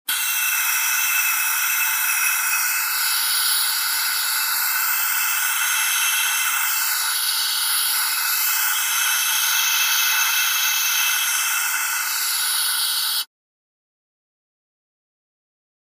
Hollow Air Release Through Tube